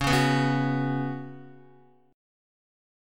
C#7sus4 chord